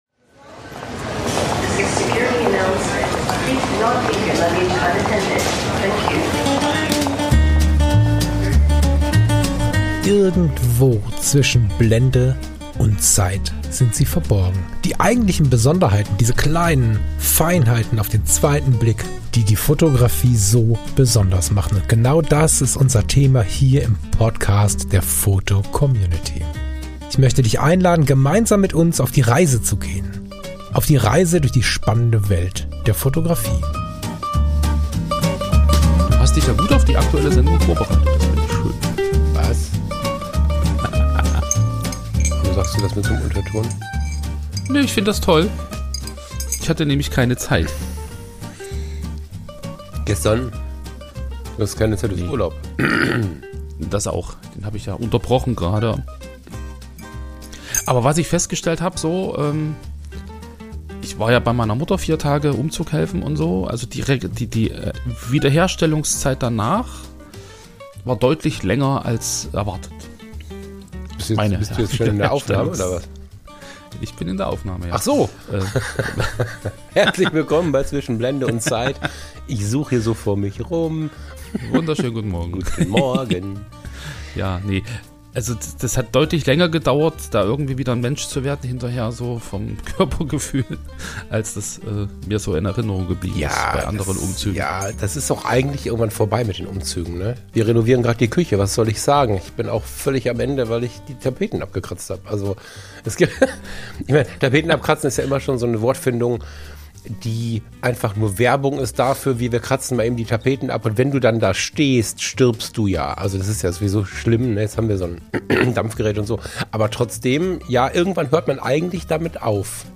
Fotografischer Mittwochs-Talk